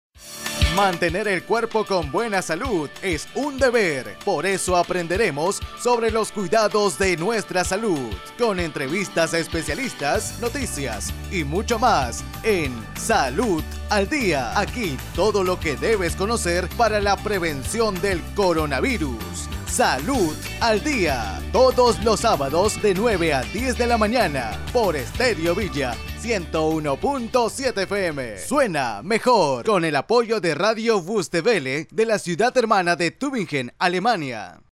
000-PROMO_GENERAL_SALUD_AL_DIA.mp3